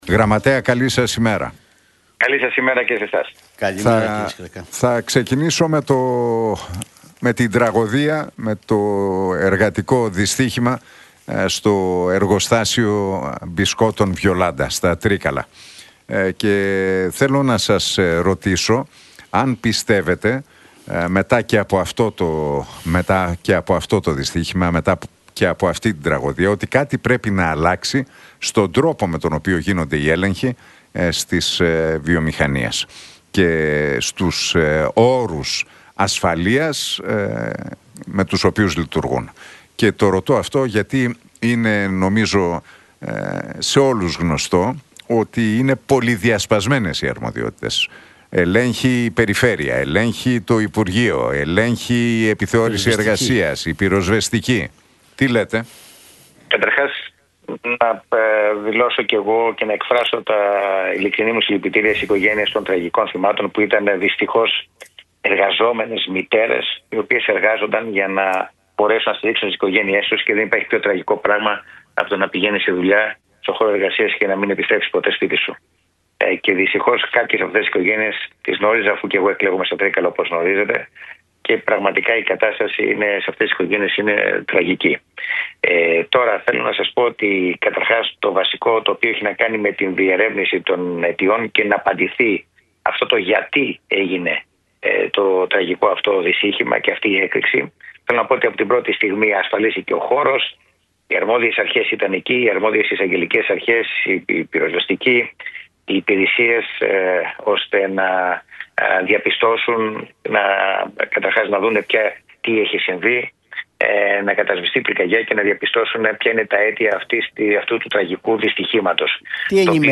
Σκρέκας στον Realfm 97,8: Θα αποδοθούν ευθύνες για την τραγωδία στα Τρίκαλα – Στο τέλος της ημέρας η ΝΔ θα λάβει ισχυρή εντολή για ισχυρή κυβέρνηση